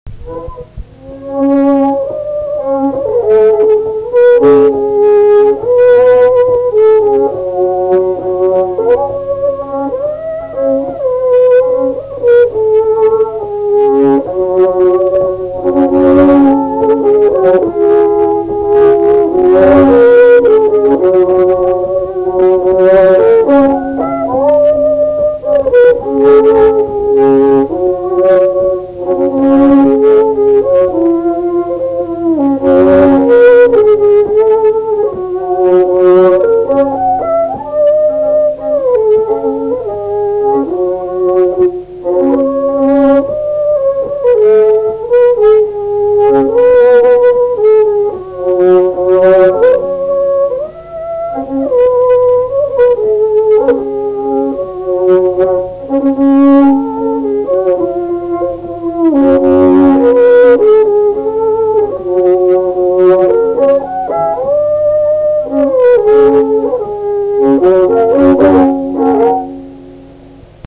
Valsas
šokis